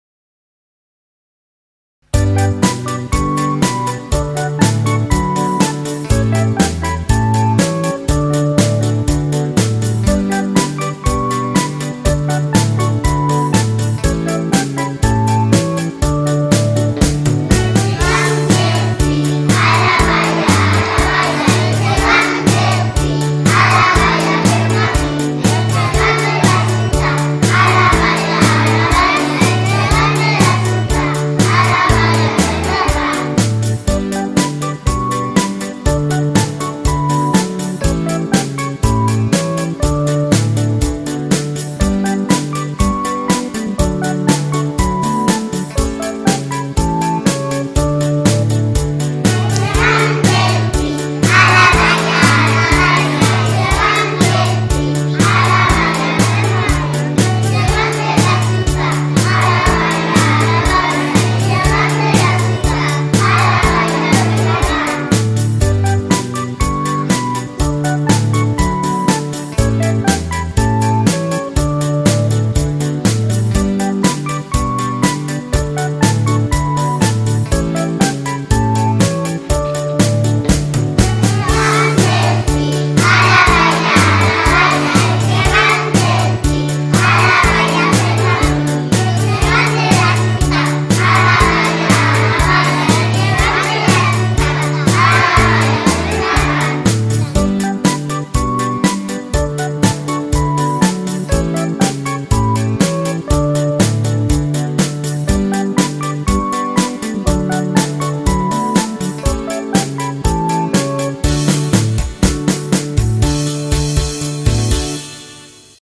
“EL GEGANT DEL PI”, cantat per alumnes de p-4 (3 classes de 25-26 alumnes)